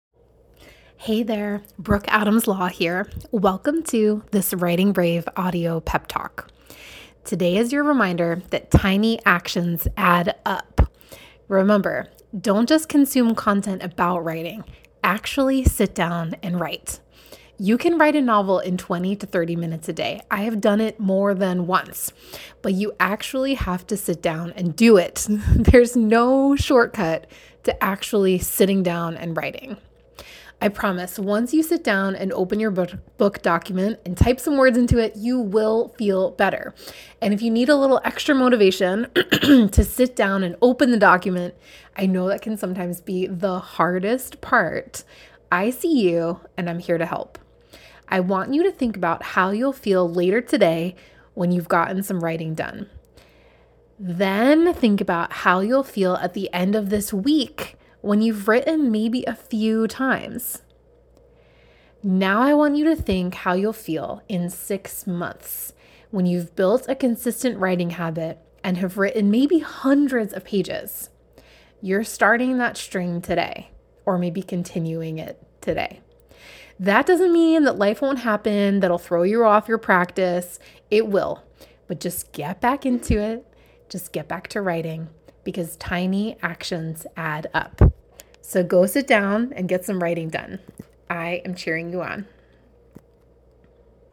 Every week for the next six weeks I’m sending you an audio pep talk.